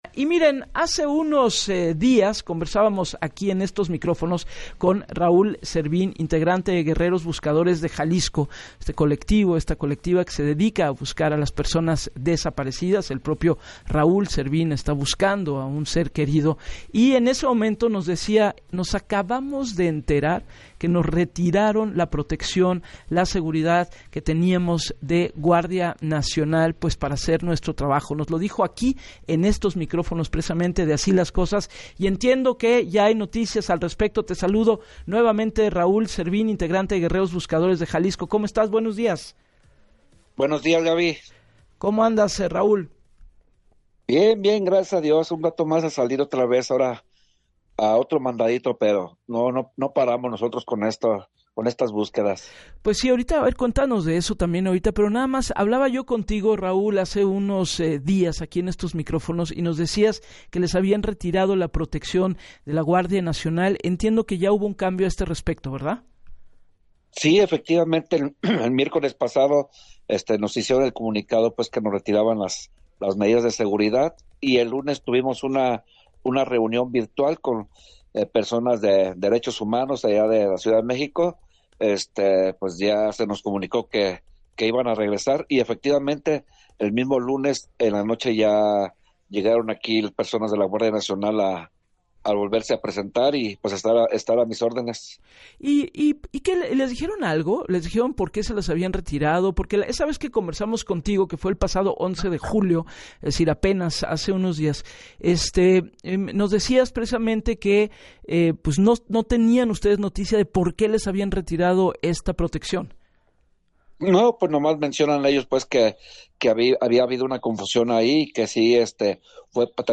en entrevista para “Así las Cosas” con Gabriela Warkentin.